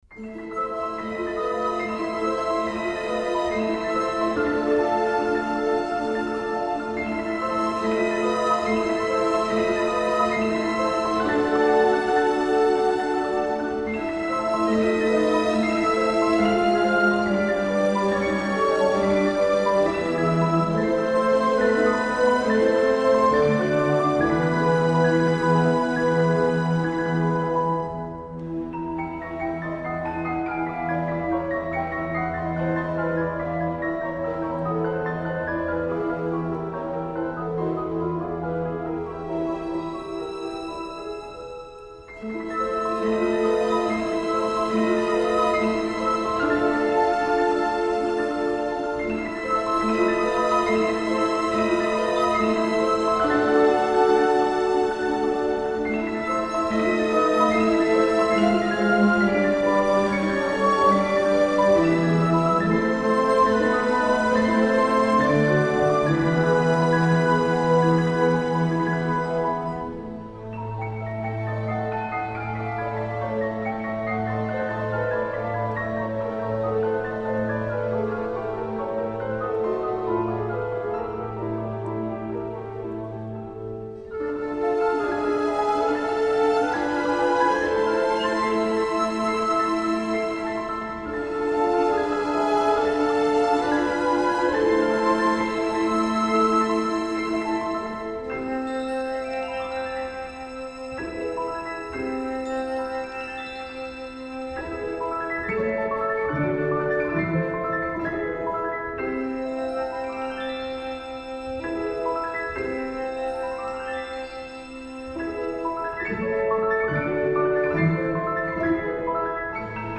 magical piece of twinkling, flowing, cascading music